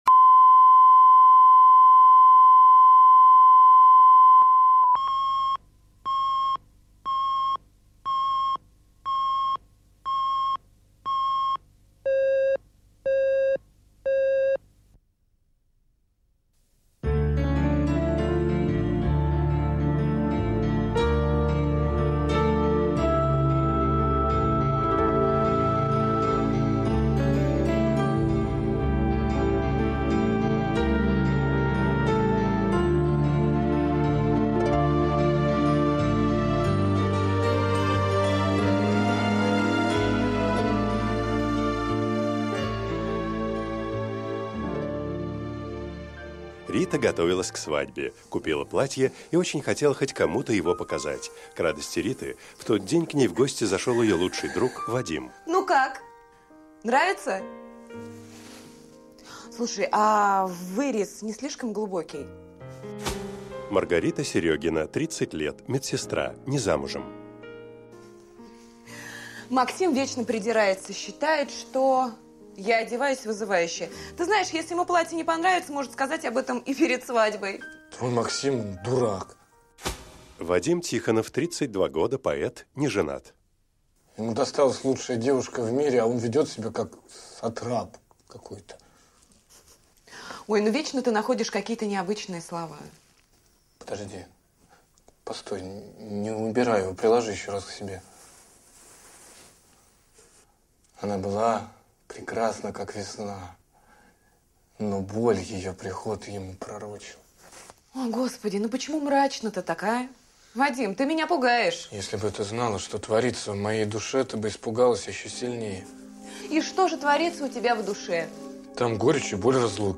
Аудиокнига Волк в овечьей шкуре | Библиотека аудиокниг